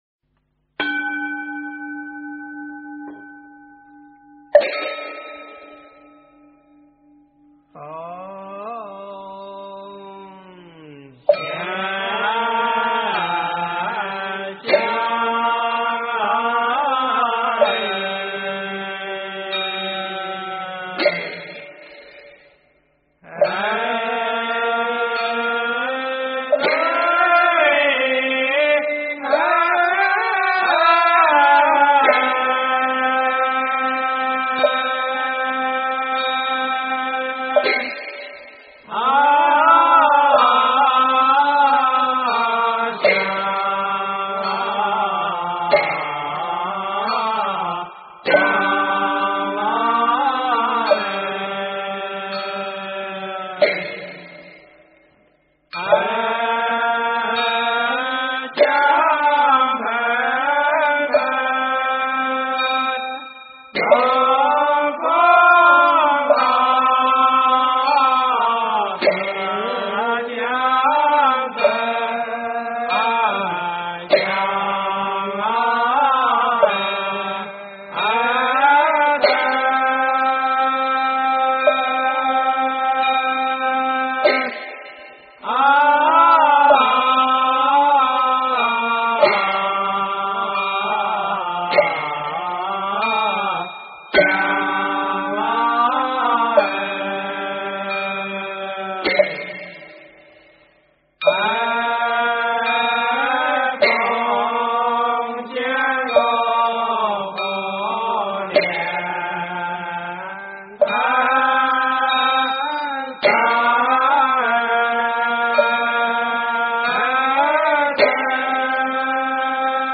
献供赞（唱诵）--文殊院
献供赞（唱诵）--文殊院 经忏 献供赞（唱诵）--文殊院 点我： 标签: 佛音 经忏 佛教音乐 返回列表 上一篇： 语加持--希阿博荣堪布 下一篇： 普庵咒（唱诵）--文殊院 相关文章 药师佛拜愿--如是我闻 药师佛拜愿--如是我闻...